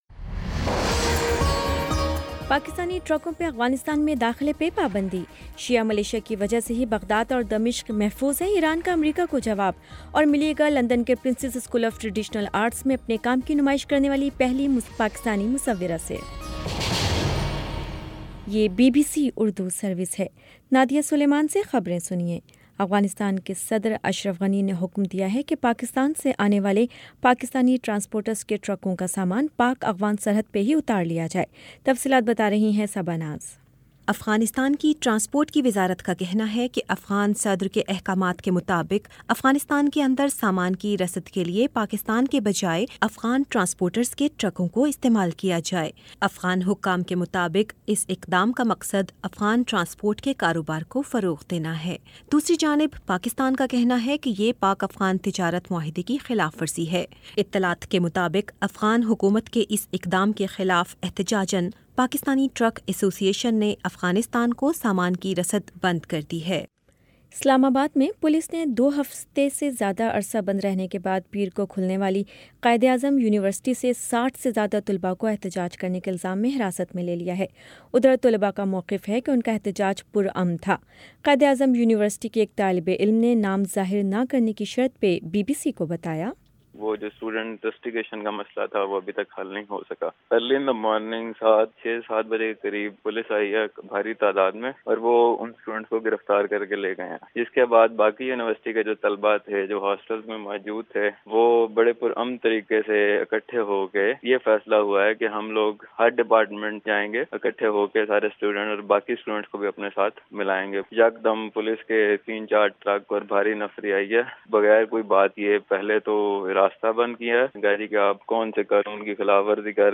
اکتوبر 23 : شام سات بجے کا نیوز بُلیٹن